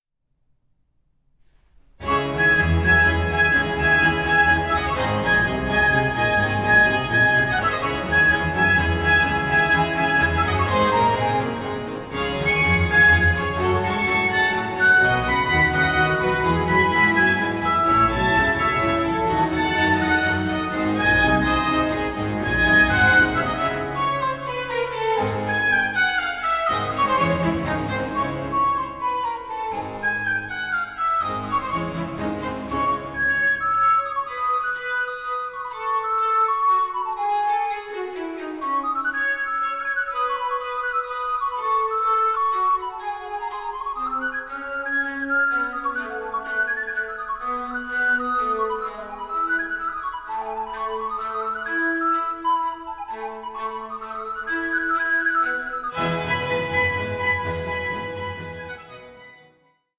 Recorder player
the recorder in place of the violin